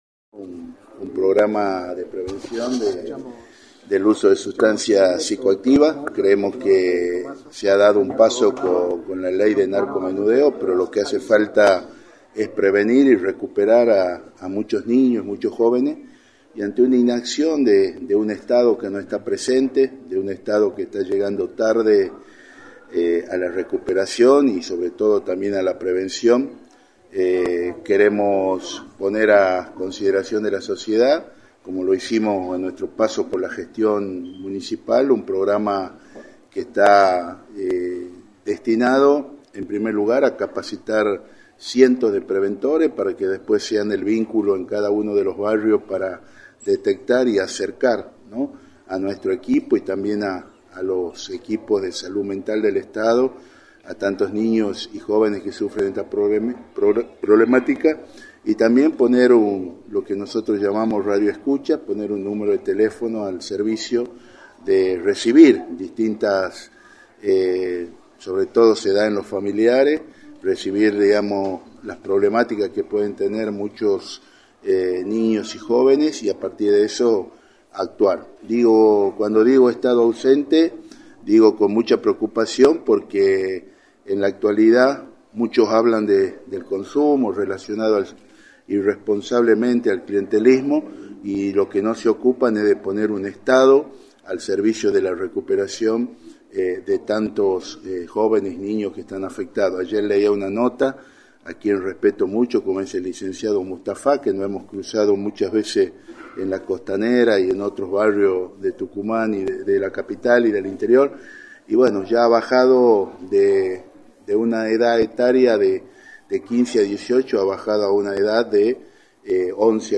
“Creemos que se ha dado un paso con la Ley de Narcomenudeo pero creemos que lo hace falta es prevenir y recuperar a muchos niños y jóvenes de las ediciones, en un Estado que no está presente, un Estado que llega tarde” remarcó Federico Masso, Legislador y candidato a Gobernador, en rueda de prensa transmitda por Radio del Plata Tucumán,  por la 93.9.